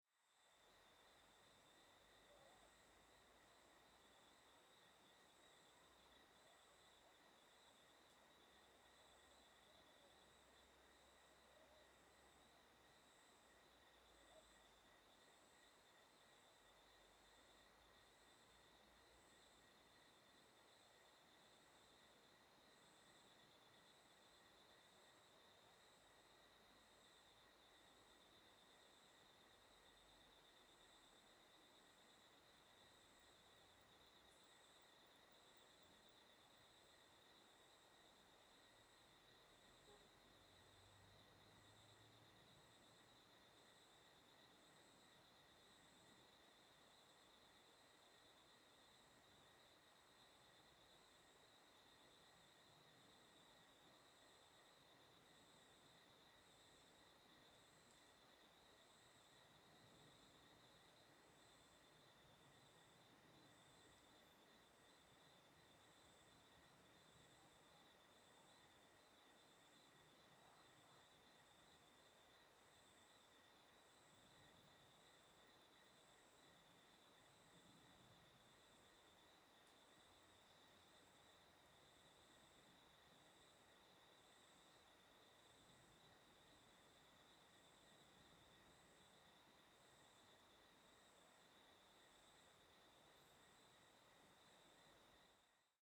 Ambiencia Noite em trilha na Pousada Caminho de Santiago com Riacho ao Fundo
Grilo , Mata fechada , Noite , Riacho , Trilha
Surround 5.1
CSC-05-035-GV - Ambiencia Noite em trilha na Pousada Caminho de Santiago com Riacho ao Fundo.wav